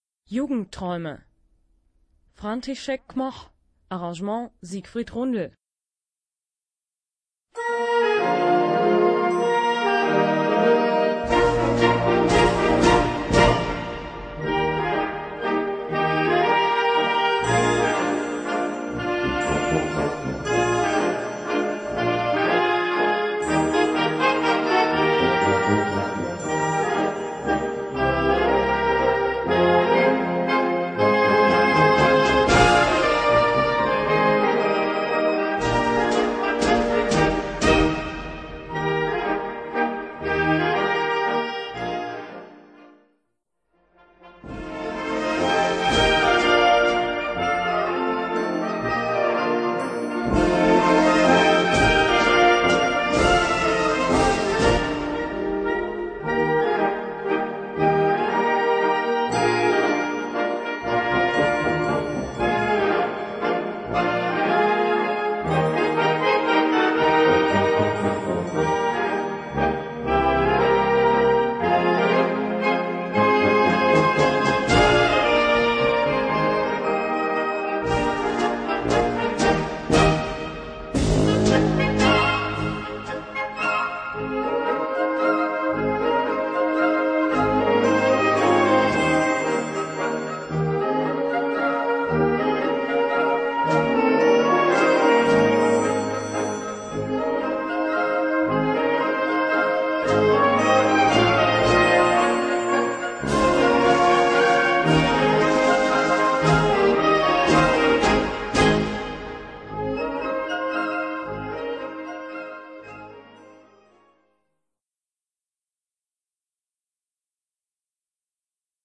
Gattung: Mazurka
Besetzung: Blasorchester